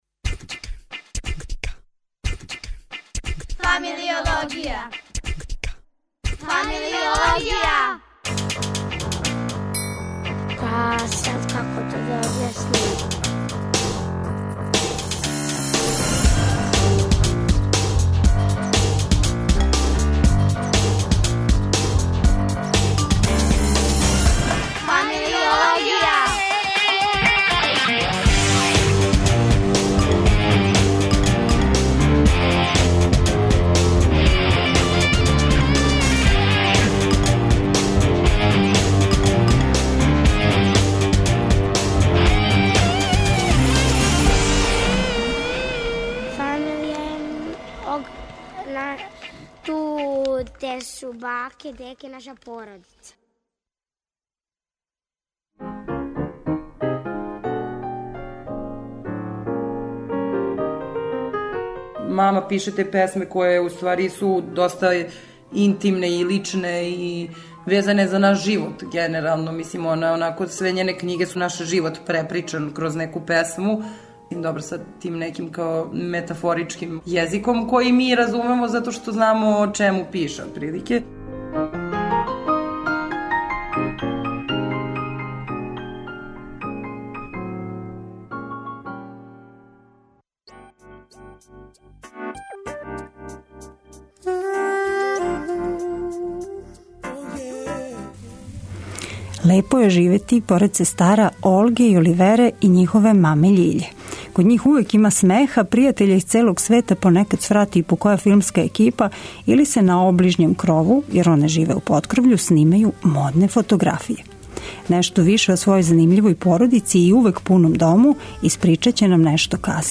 гошћа у студију